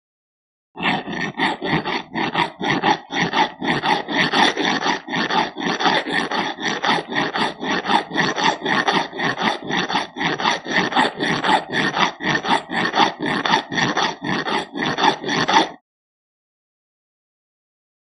Filing Metal; Filing Metal. Regular Strokes.